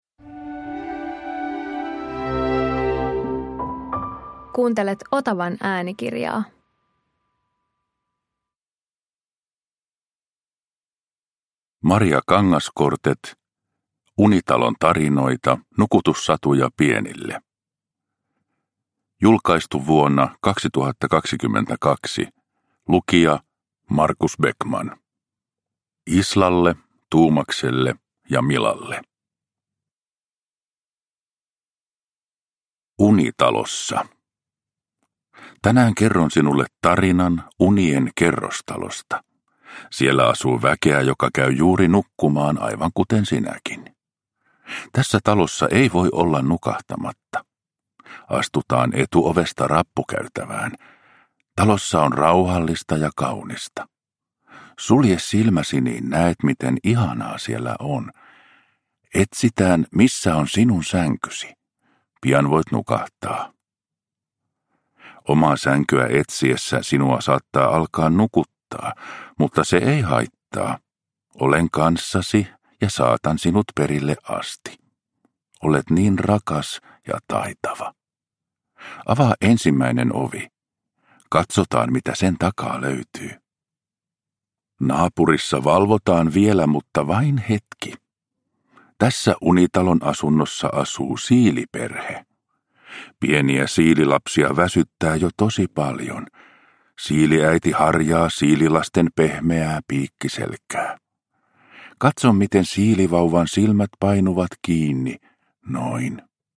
Unitalon tarinoita – Ljudbok – Laddas ner